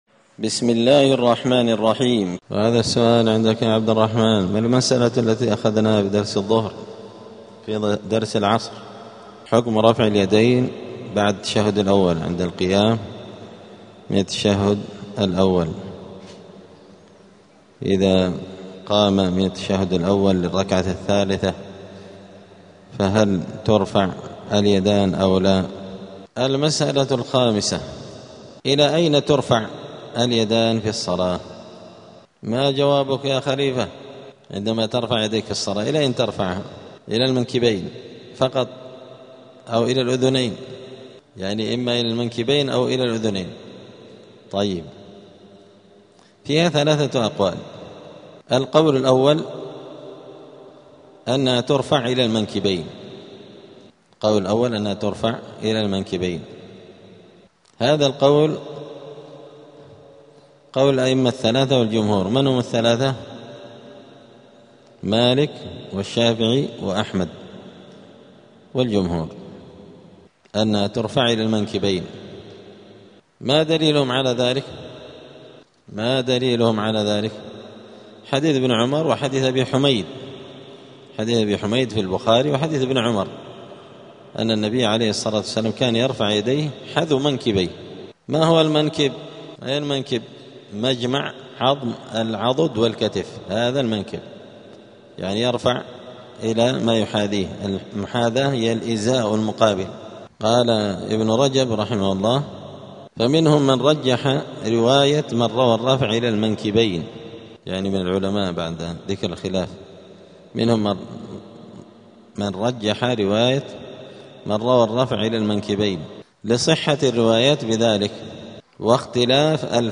دار الحديث السلفية بمسجد الفرقان قشن المهرة اليمن
*الدرس الواحد والثمانون بعد المائة [181] باب صفة الصلاة {إلى أين ترفع اليدان في الصلاة}*